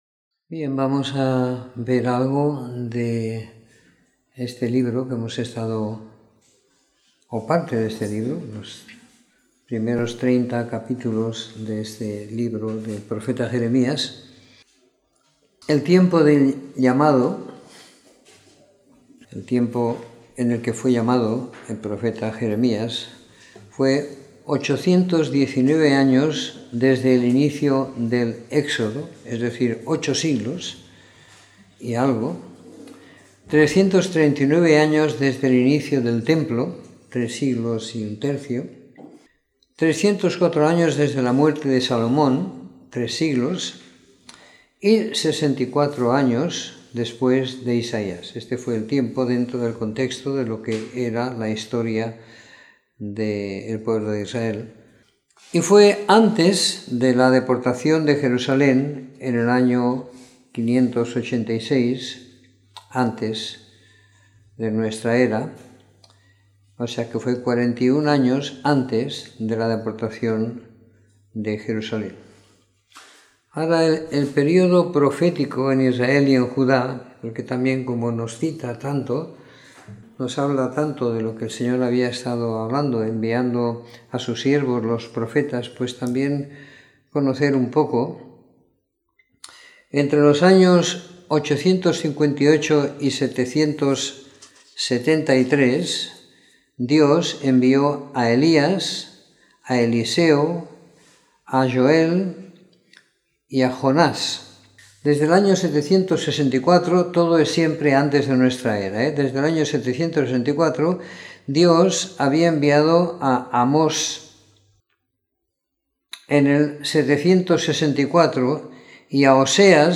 Comentario en el libro de Jeremías del capítulo 1 al 30 siguiendo la lectura programada para cada semana del año que tenemos en la congregación en Sant Pere de Ribes.